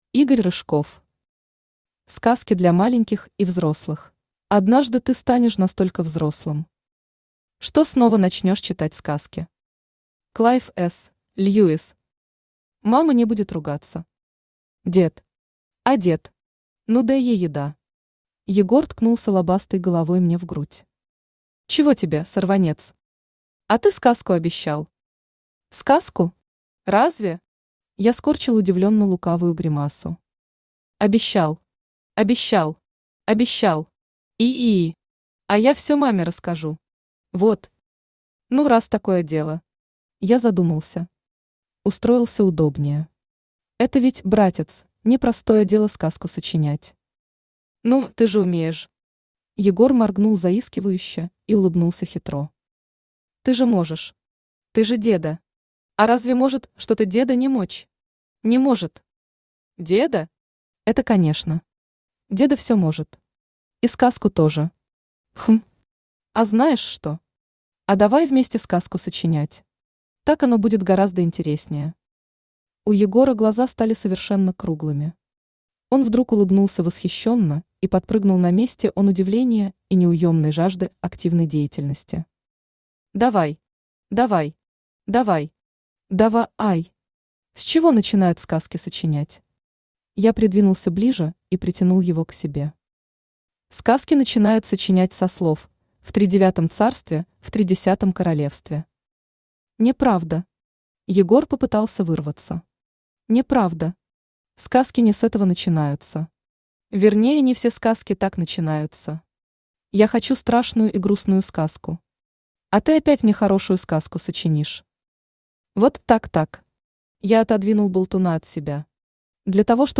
Аудиокнига Сказки для маленьких и взрослых | Библиотека аудиокниг
Aудиокнига Сказки для маленьких и взрослых Автор Игорь Рыжков Читает аудиокнигу Авточтец ЛитРес.